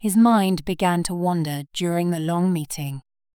Kiejtés: /ˈwɒn.dər/